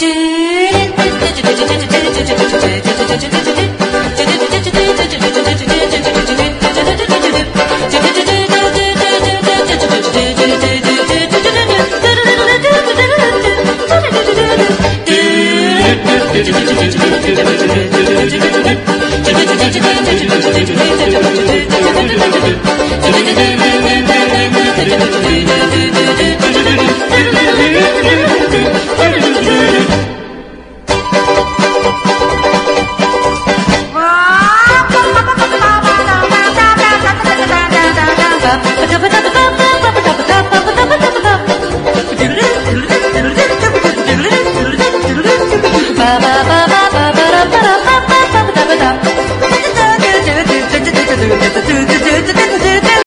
JAPANESE NEW WAVE
和モノ・ルンバ
哀愁裏打ち歌謡
エスノでスペースエイジな桃源郷ラウンジ